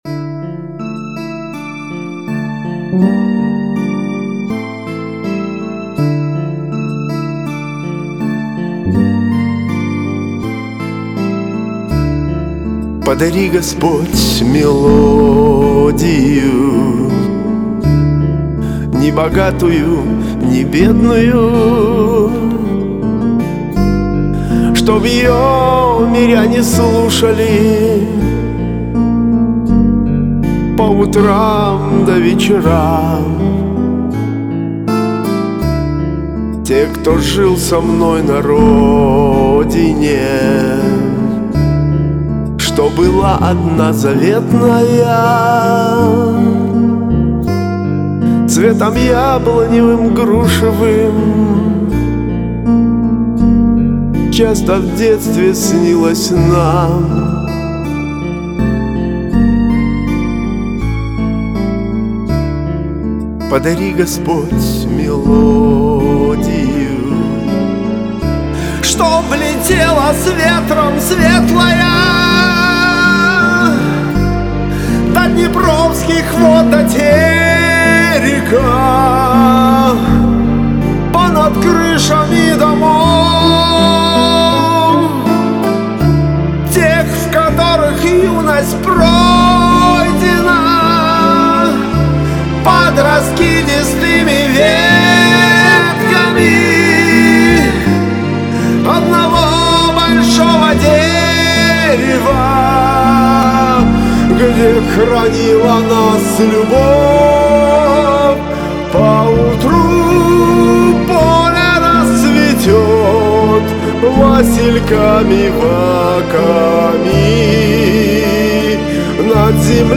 двухголосье он тоже сам прописывал